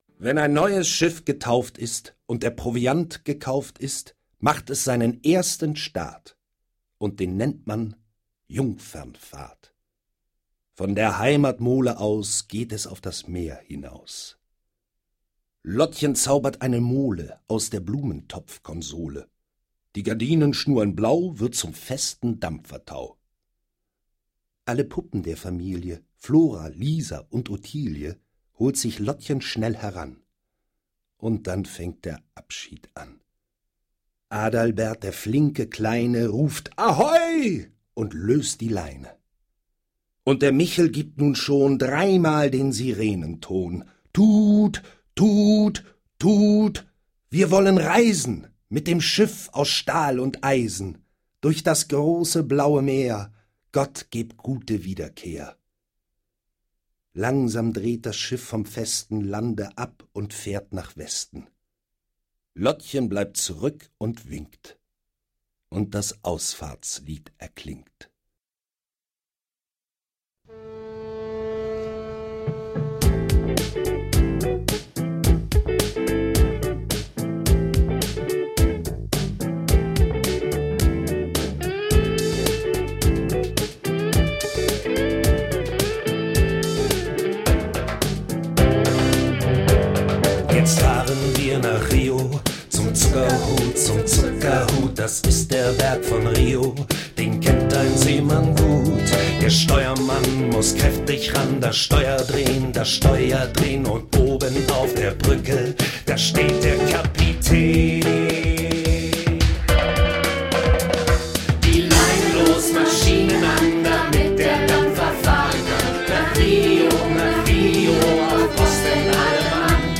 Ein Singspiel
mit einem Hamburger Kinderchor
Kinderlieder